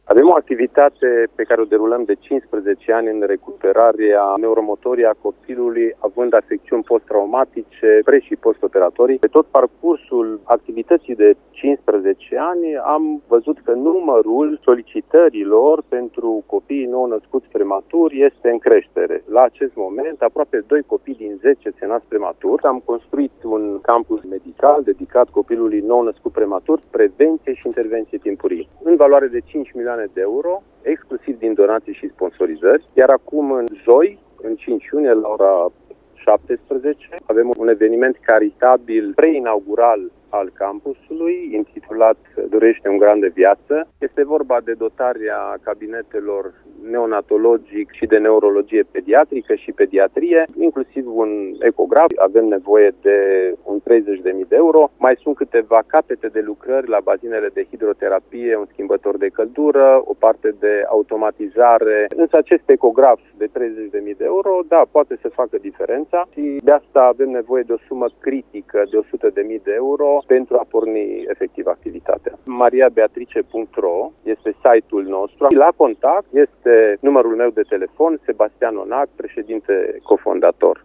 Invitat la postul nostru de radio